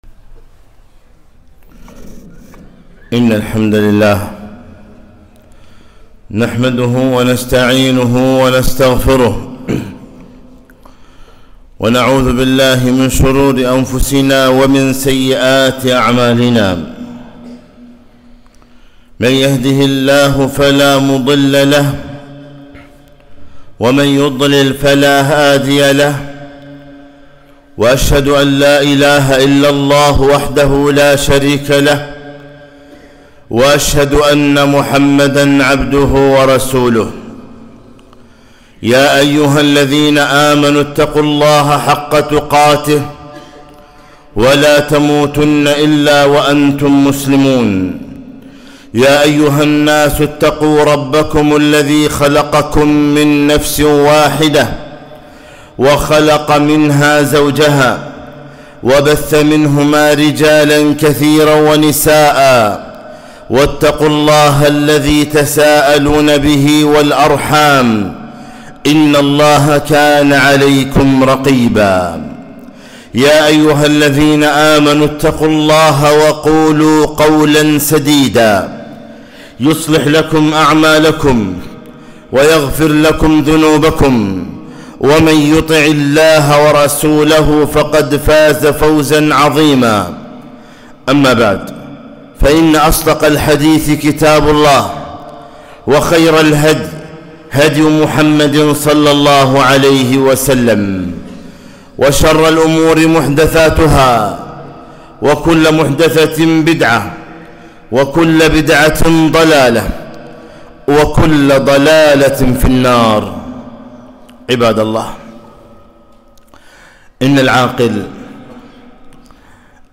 خطبة - من أحمق الناس؟